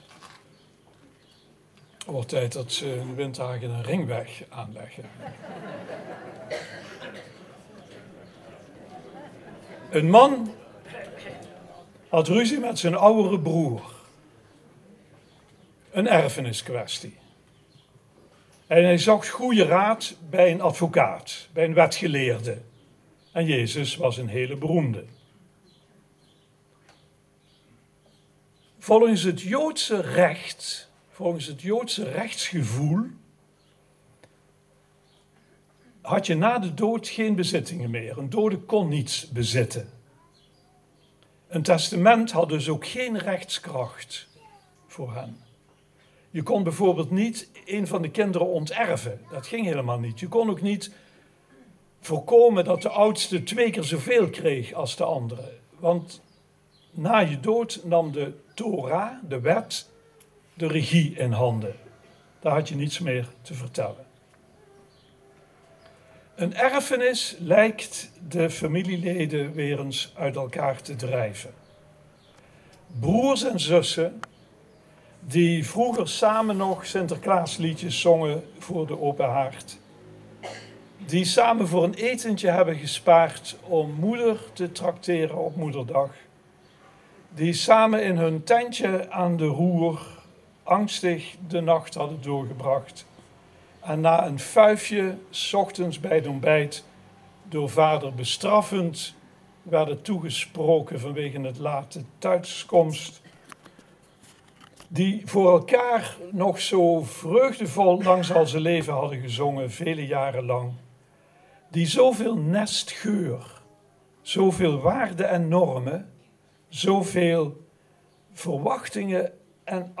De datum waarop de preek gehouden is ligt gewoonlijk een week later